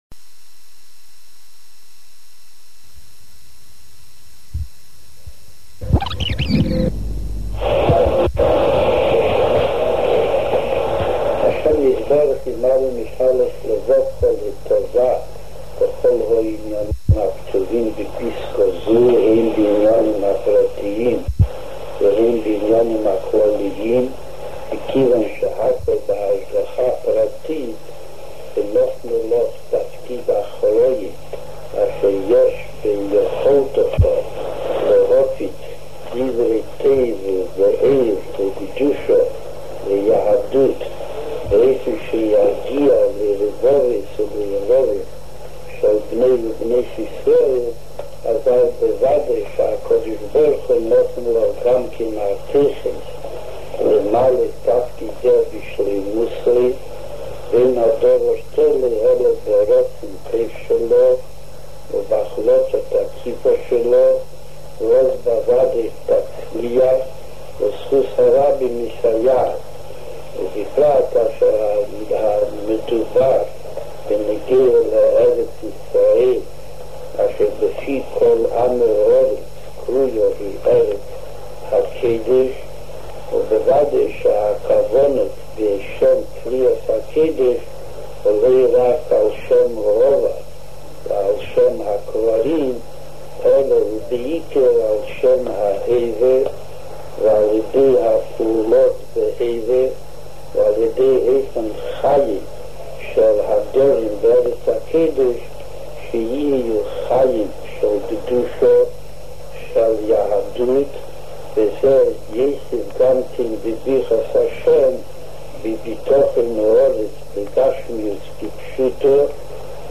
L’un de ses faits les plus marquants est peut-être lorsqu’il a interviewé le Rabbi pour la station de radio Kol Israël.